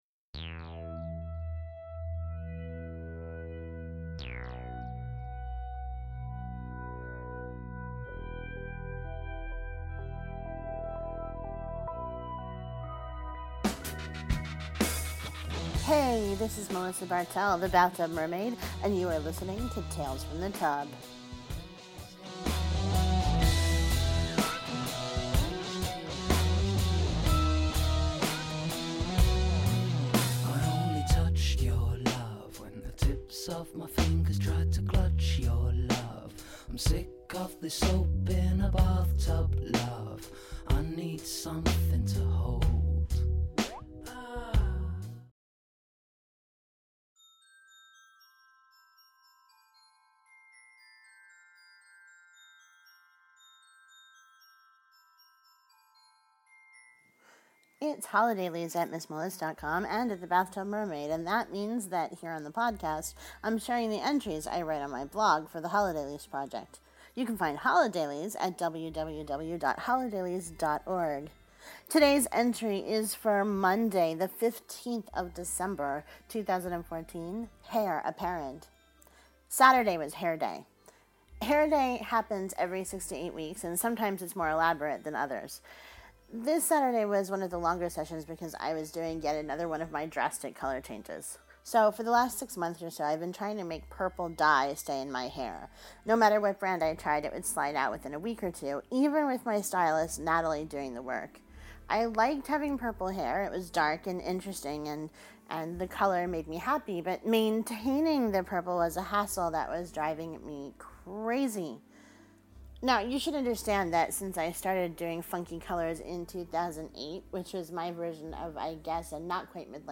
The standard opening song is “Soap in a Bathtub,” by Stoney.